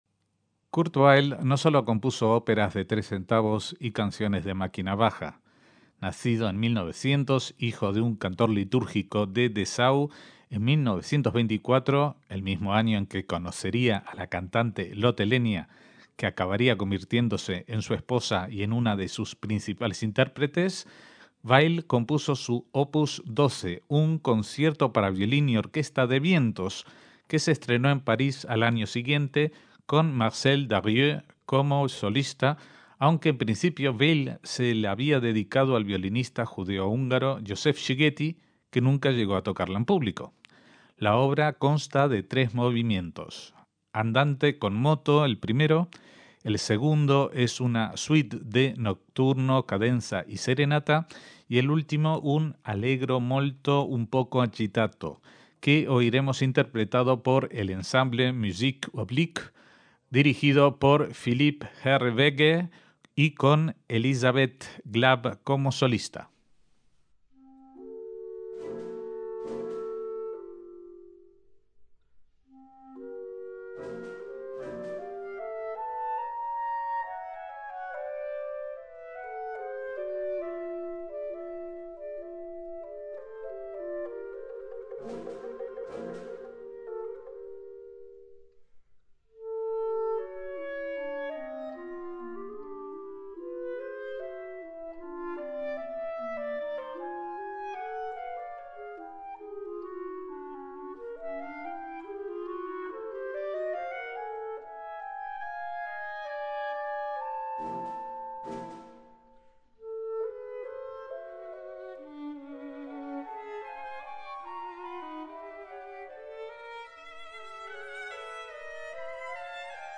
Concierto para violín y orquesta de vientos de Kurt Weill